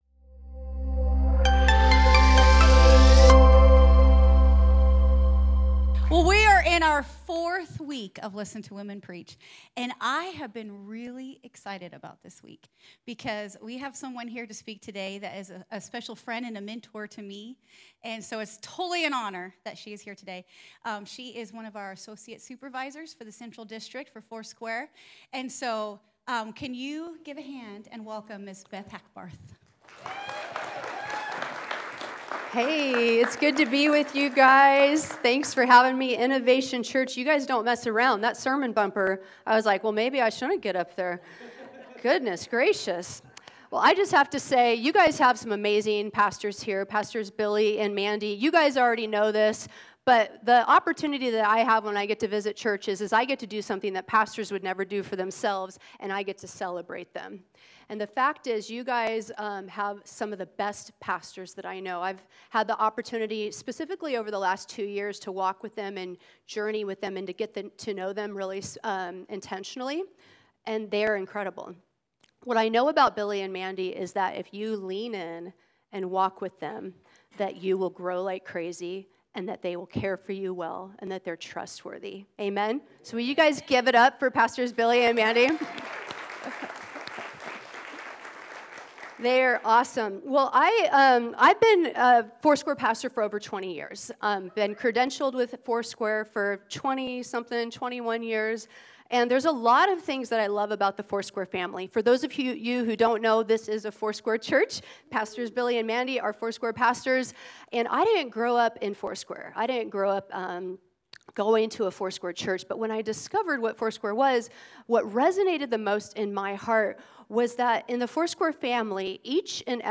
Sermons | Innovation Church